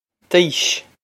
d'aois d-eesh
Pronunciation for how to say
d-eesh
This is an approximate phonetic pronunciation of the phrase.